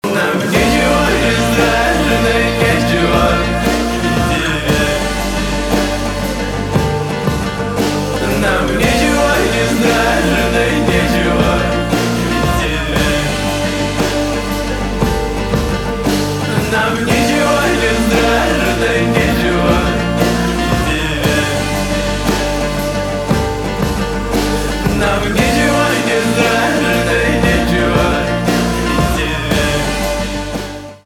рок , русский рэп , русский рок , гитара , барабаны
грустные